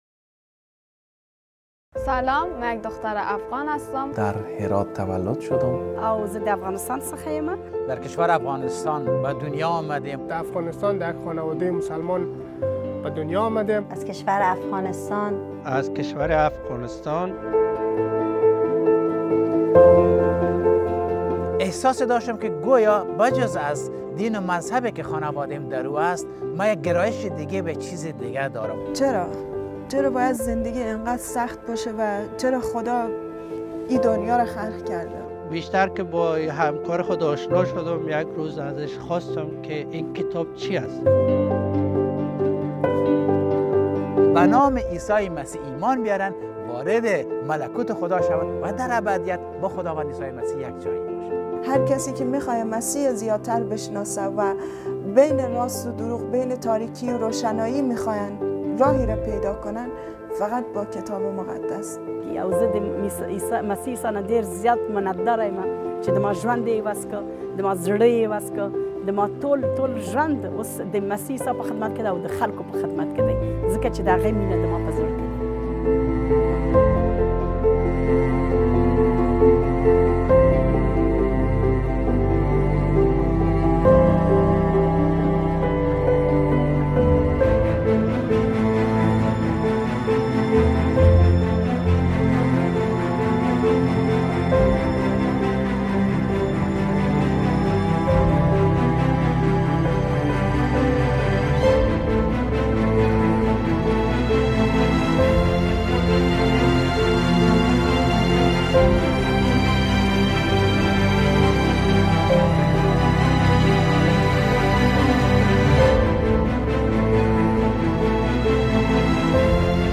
این تریلر فصل اول پروگرام "داستان من" است. هشت مرد و زن افغان داستان خود را می‌‌گویند که چیگونه به عیسی مسیح ایمان آوردند و انتخاب کردند که پیرو او شوند.